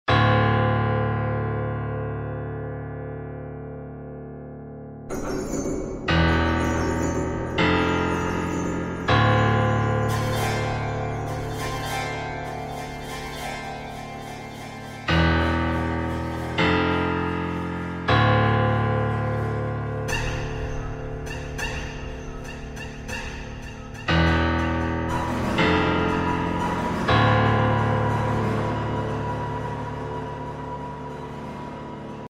Звуки тревоги
В коллекции — резкие сирены, нарастающие гудки, тревожные оповещения и другие эффекты, усиливающие напряжение.